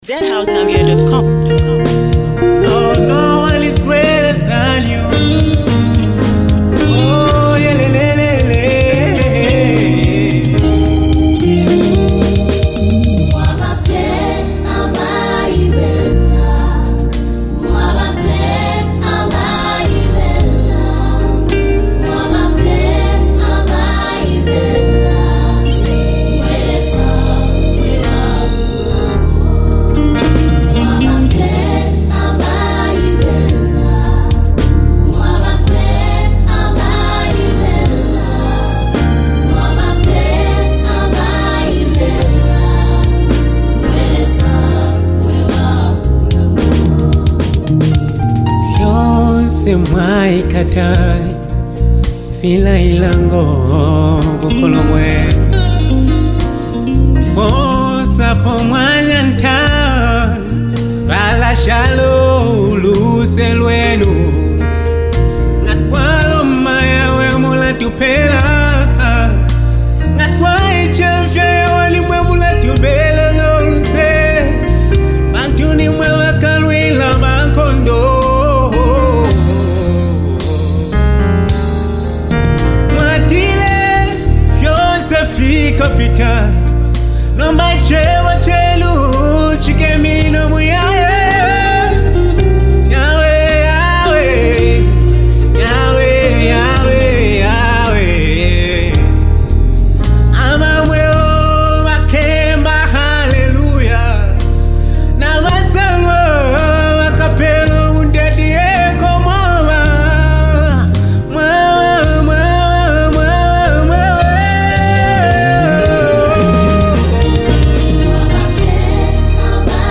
gospel anthem